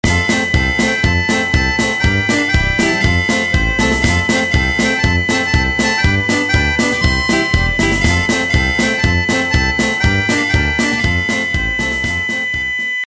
Для получения этой мелодии в формате MP3 (с голосом)
• Пример мелодии содержит искажения (писк).